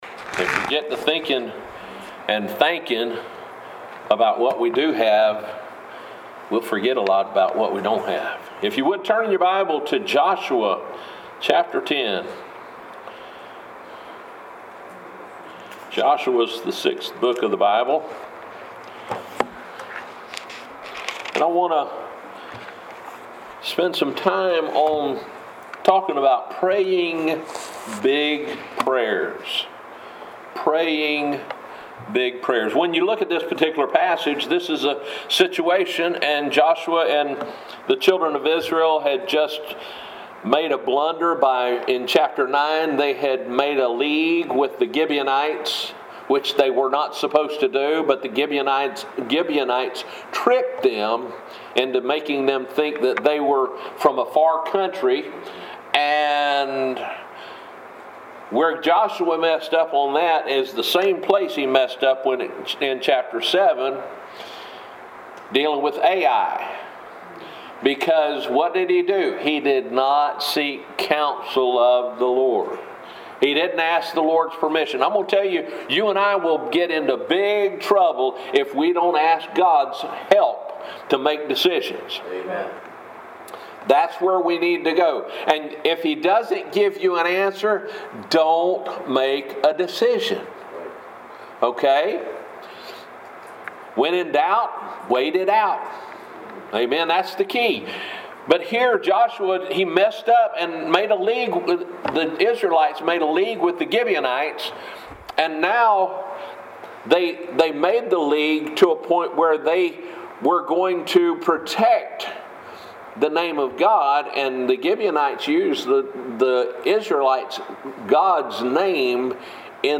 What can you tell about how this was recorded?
10/25/2020 Morning Service